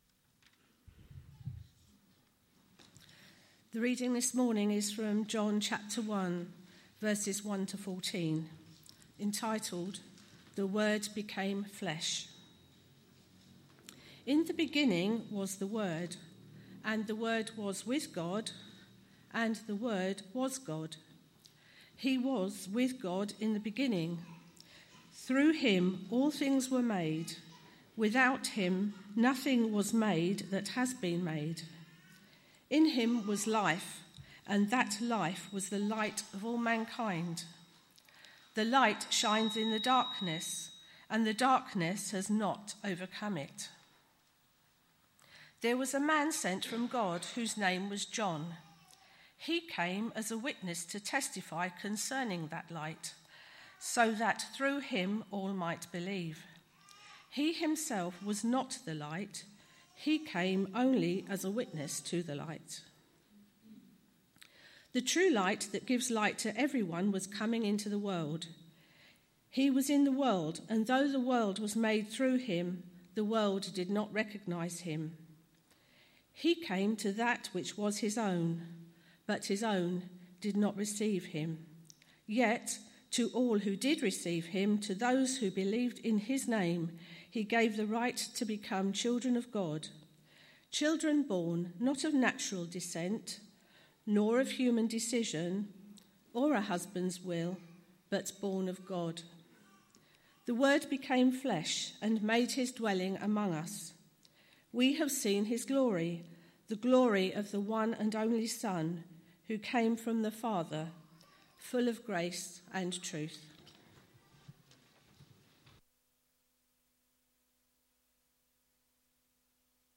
A message from the series "Pride and Humility."